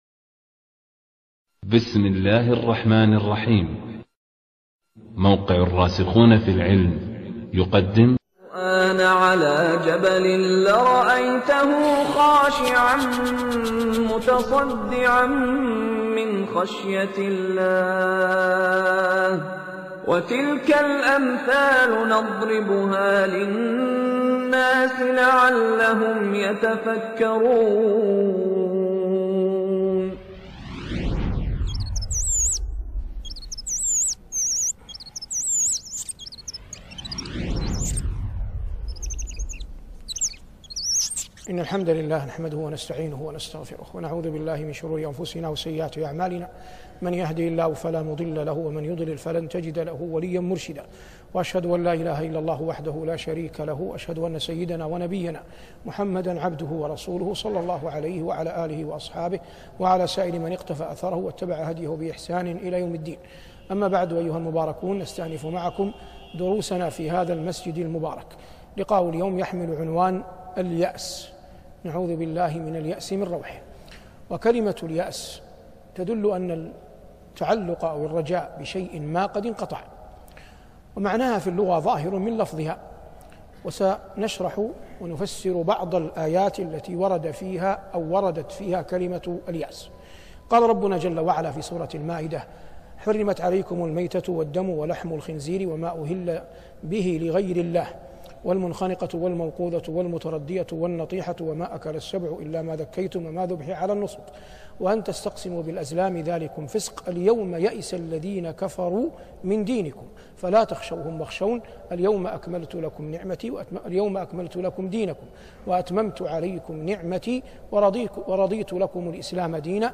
شبكة المعرفة الإسلامية | الدروس | اليأس |صالح بن عواد المغامسي